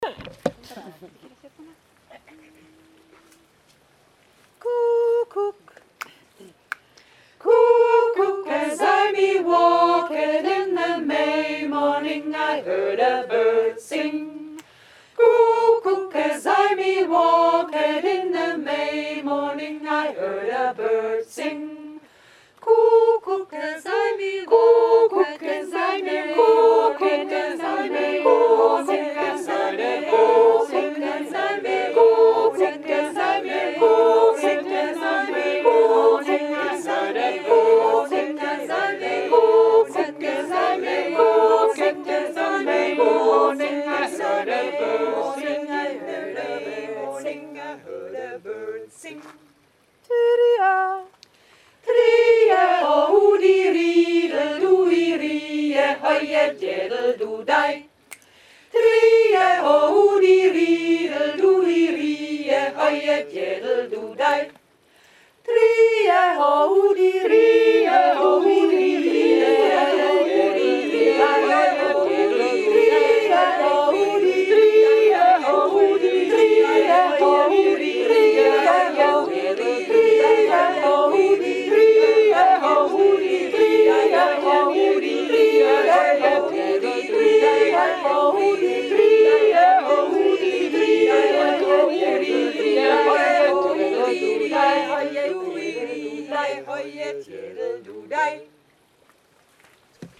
englischer Jodler
JA hier findet ihr die Aufnahmen unserer Jodler , von mir eingesungen.